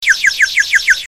clock11.ogg